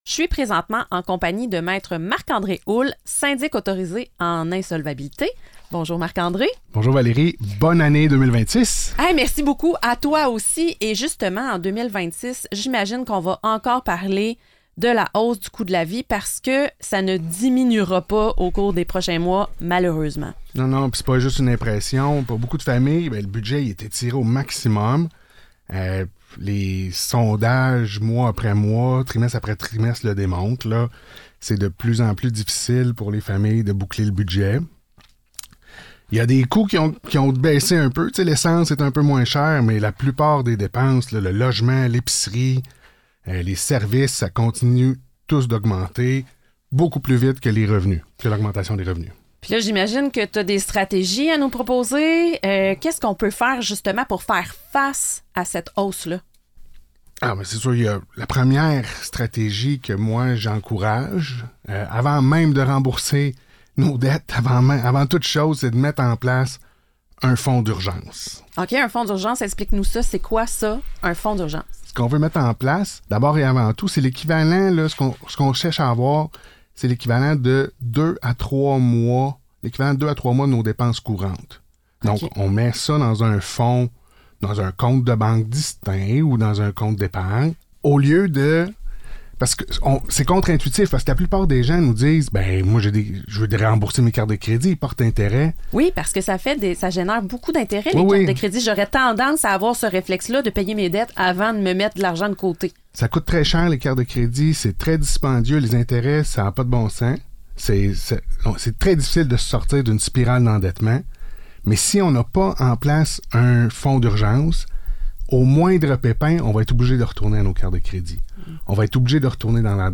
Chronique Radio 106.9 FM :